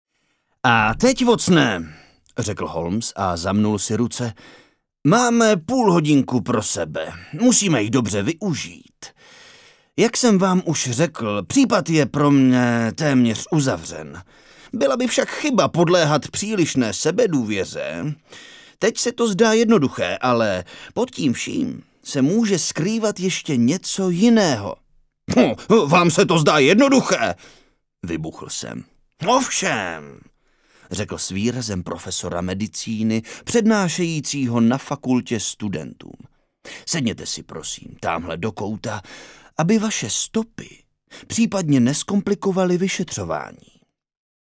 ukázka audio kniha verze 1
ukazka-audio-kniha-verze-1.mp3